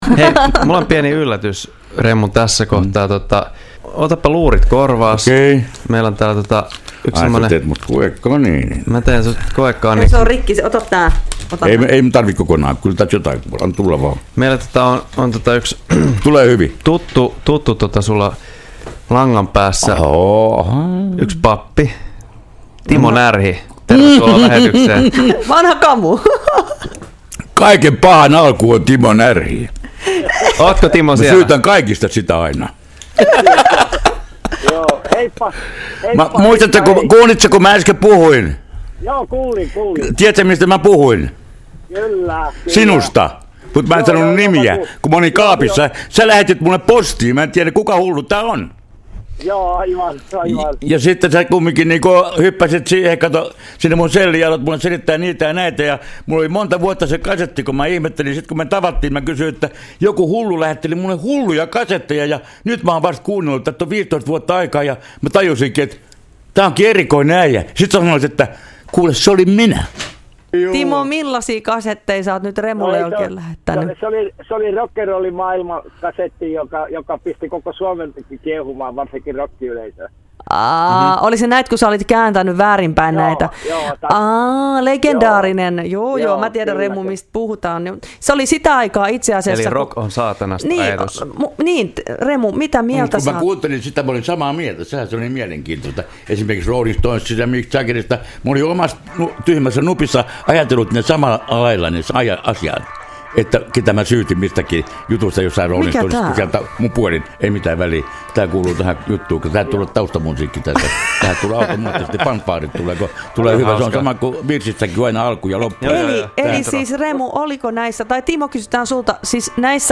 Herätys yllätti Remun suorassa lähetyksessä